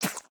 assets / minecraft / sounds / mob / fox / spit3.ogg
spit3.ogg